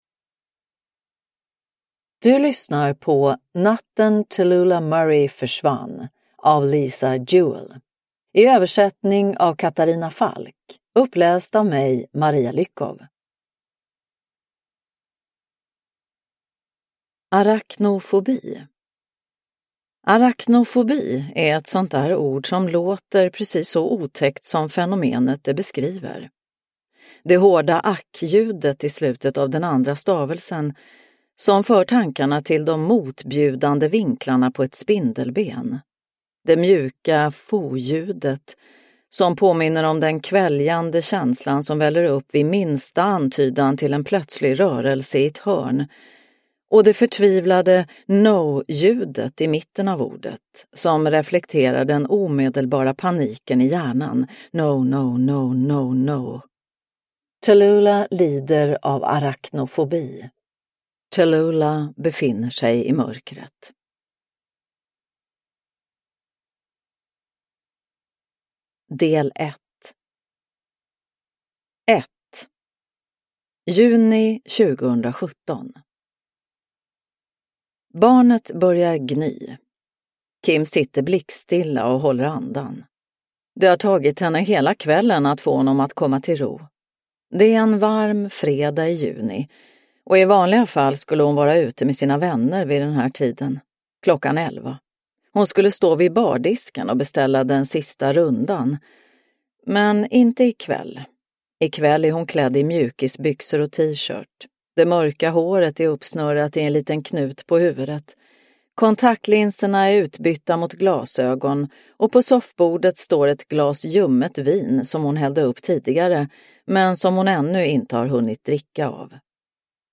Natten Tallulah Murray försvann – Ljudbok – Laddas ner